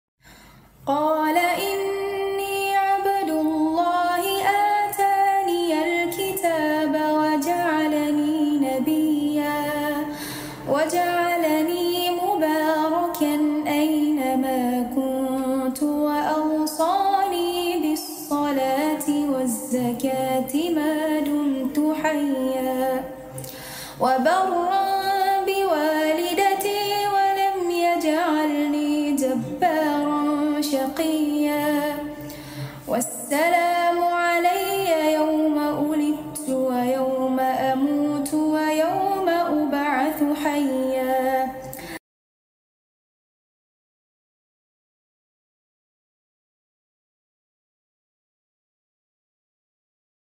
SURAH MARYAM 30 34 beautiful recitation sound effects free download